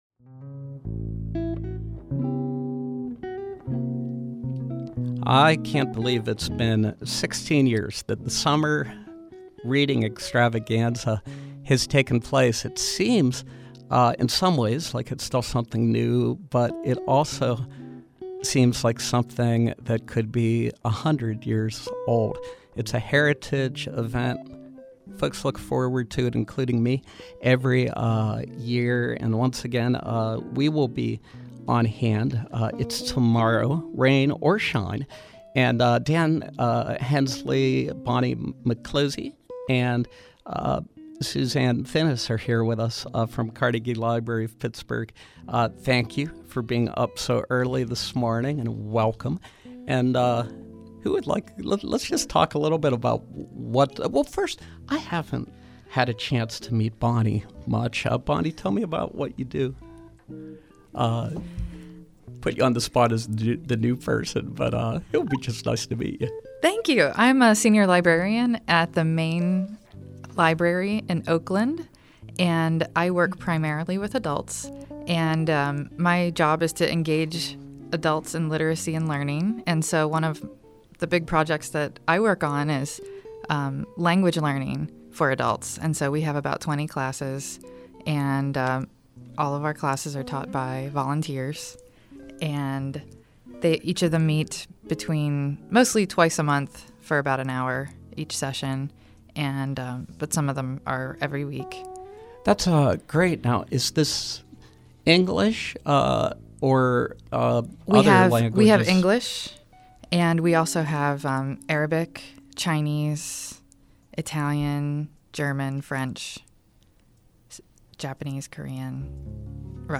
Interview: Summer Reading Extravaganza | Neighborhood Voices